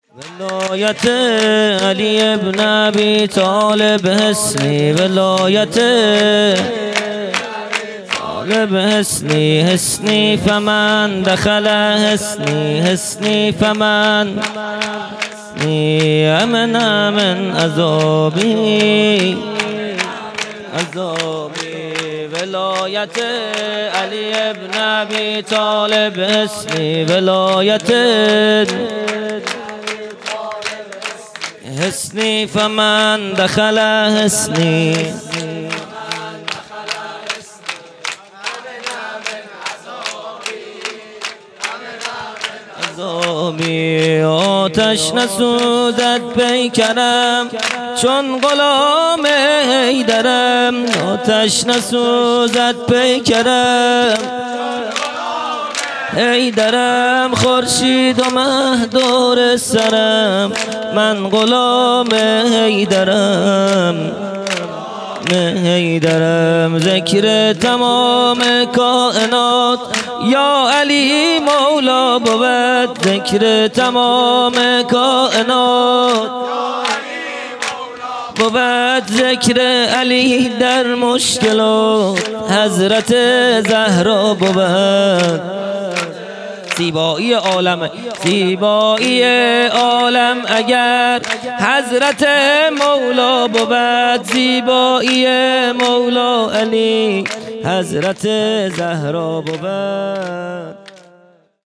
خیمه گاه - هیئت بچه های فاطمه (س) - سرود | ولایت علی بن ابیطالب حصنی
جلسۀ هفتگی (به مناسبت ولادت حضرت علی اکبر(ع))